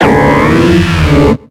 Cri de Sepiatroce dans Pokémon X et Y.